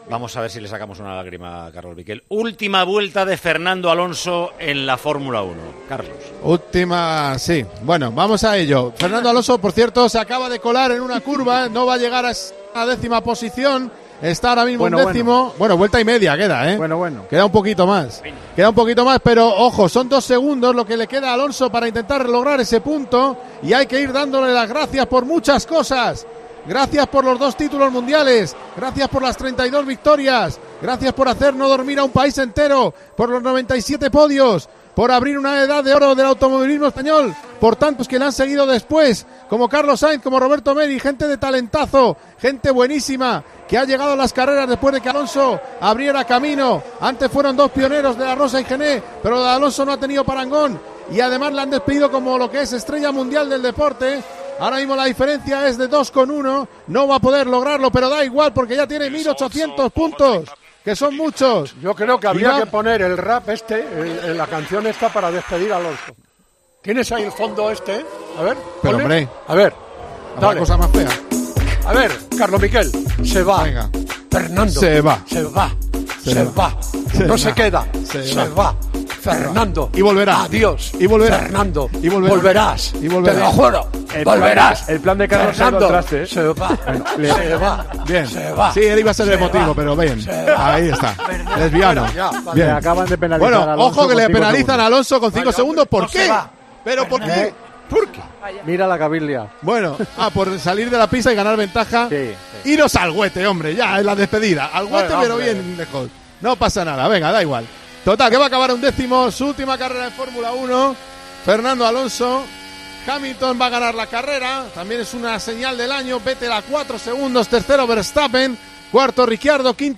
Así vivimos en Tiempo de Juego la última vuelta de Fernando Alonso en la Fórmula 1